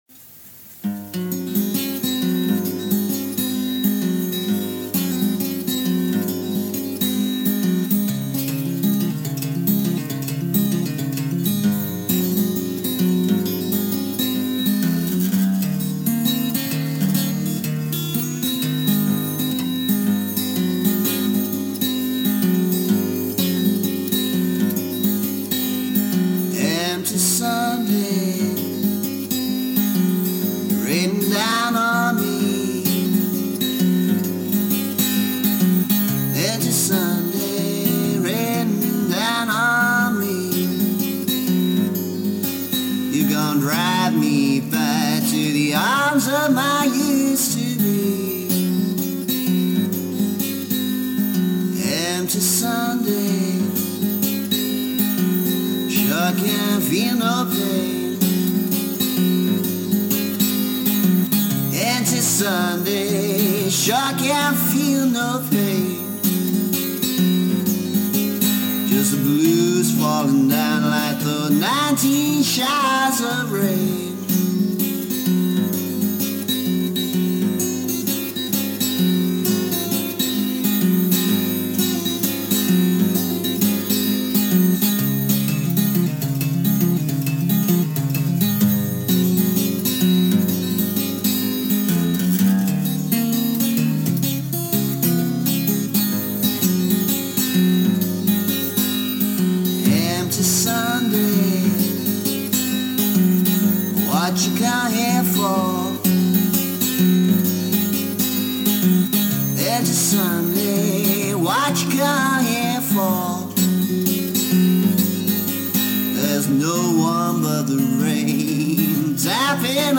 A very old recording (from cassette, not a studio recording). Remastering has raised the volume level, but degraded the guitar slightly. One of my bluesier songs.
Remastered version: